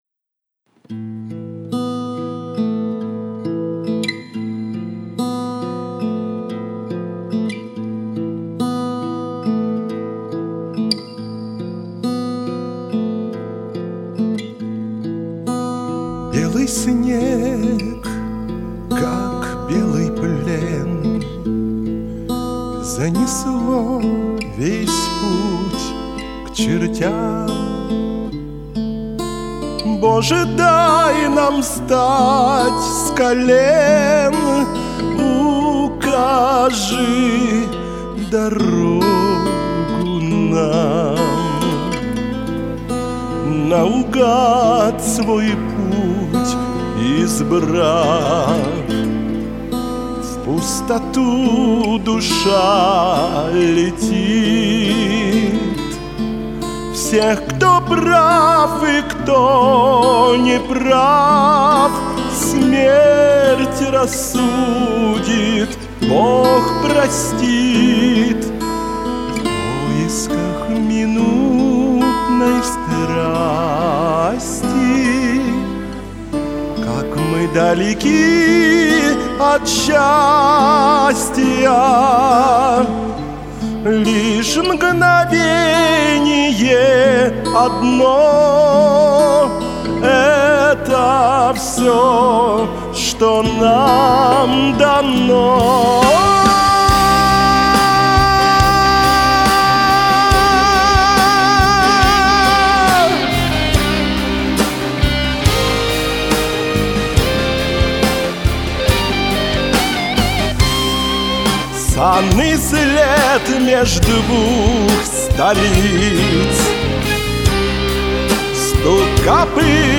Оба исполнения сильны,великолепны!